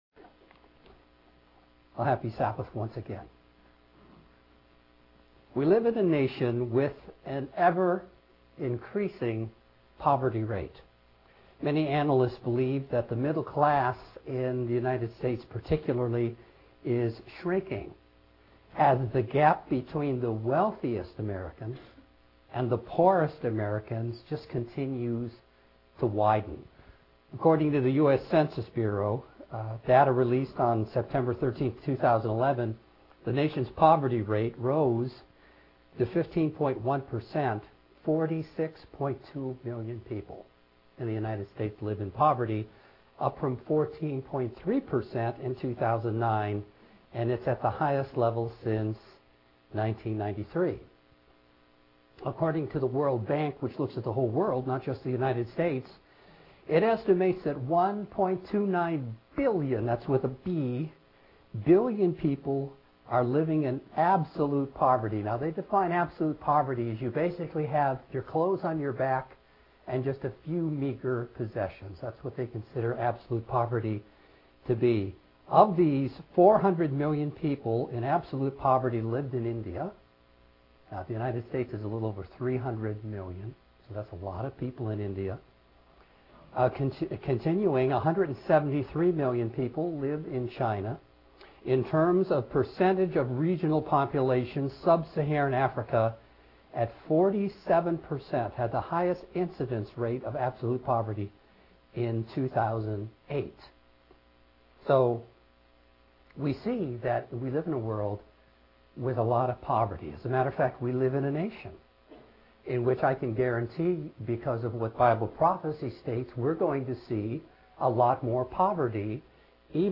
This sermon explores the biblical view of poverty and a Christian approach for the modern age.